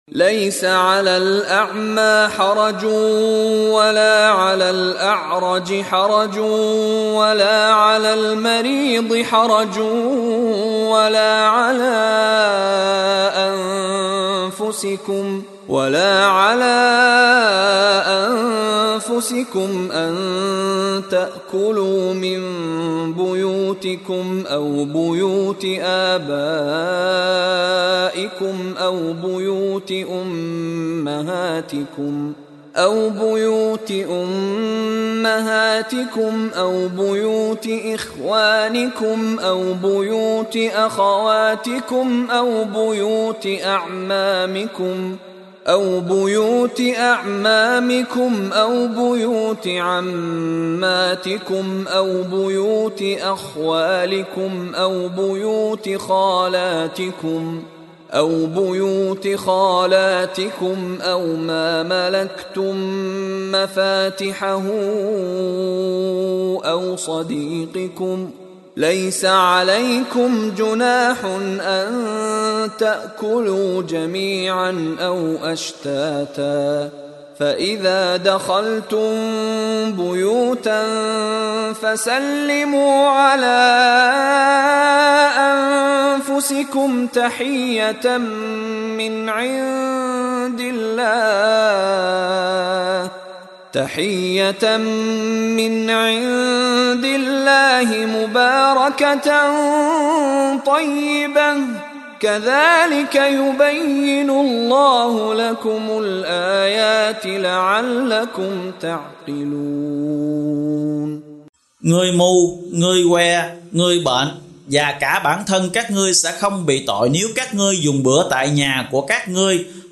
translation tác giả : Mushary bin Roshid Al-A’fasy
Đọc ý nghĩa nội dung chương An-Nur bằng tiếng Việt có đính kèm giọng xướng đọc Qur’an